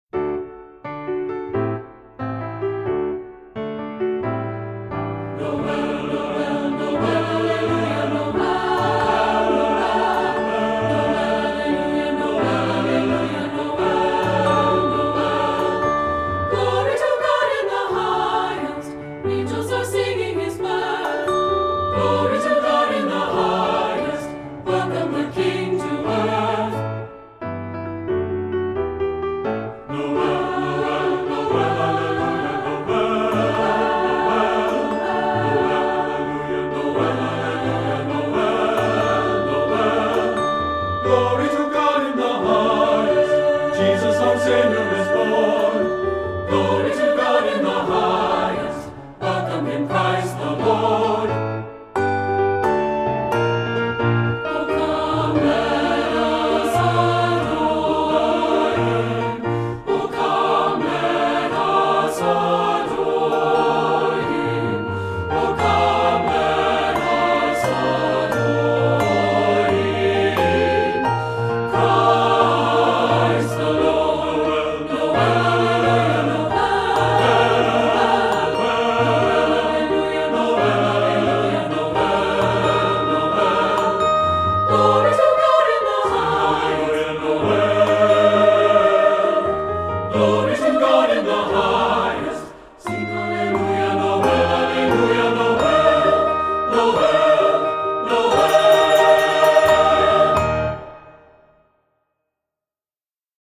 Voicing: 2-Part or SAB